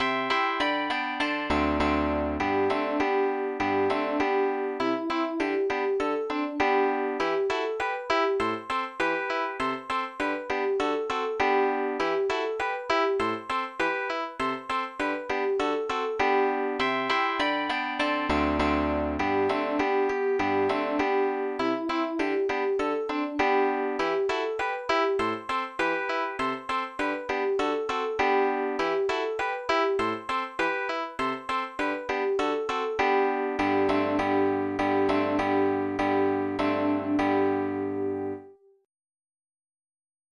Georgian MID Songs for Children